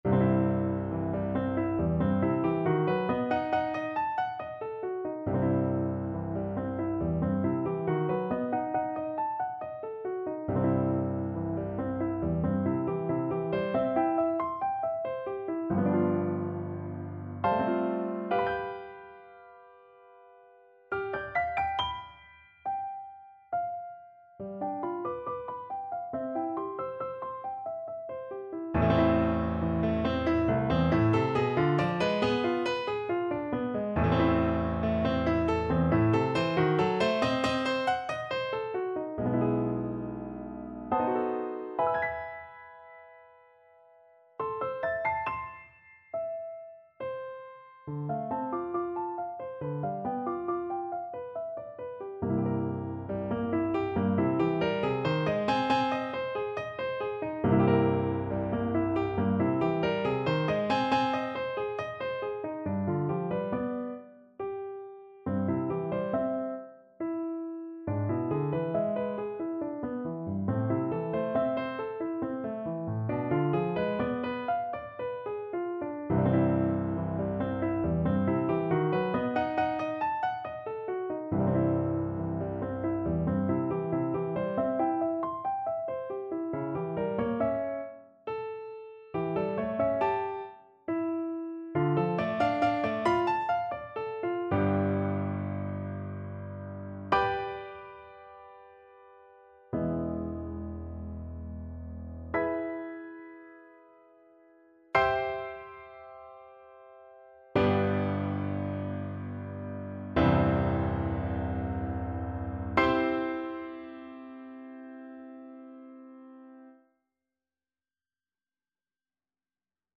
Play (or use space bar on your keyboard) Pause Music Playalong - Piano Accompaniment Playalong Band Accompaniment not yet available reset tempo print settings full screen
3/4 (View more 3/4 Music)
C major (Sounding Pitch) (View more C major Music for Viola )
~ = 69 Large, soutenu
Classical (View more Classical Viola Music)